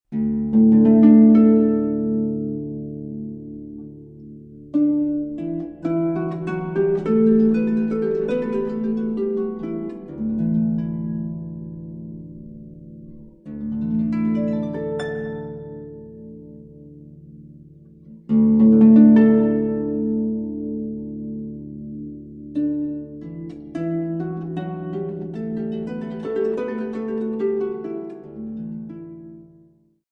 an album of superb Russian solo harp music
modern concert harp